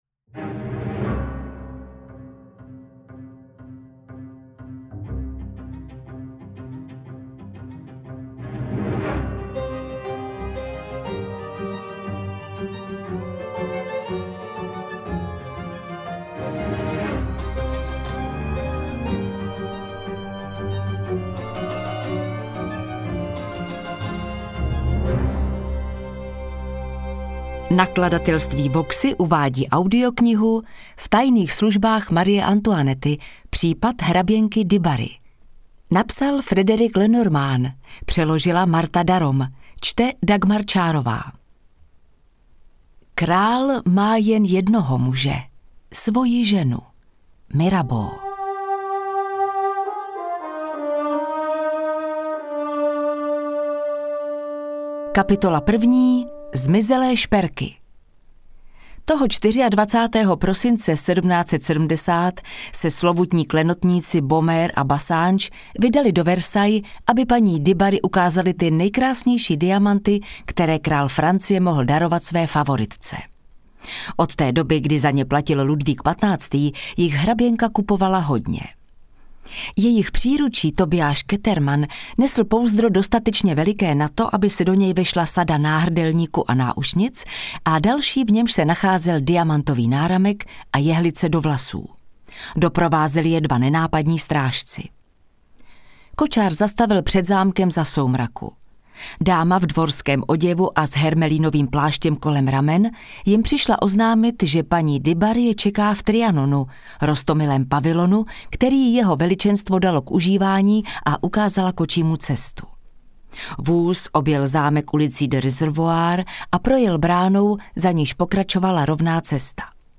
• AudioKniha ke stažení V tajných službách Marie Antoinetty: Případ hraběnky du Barry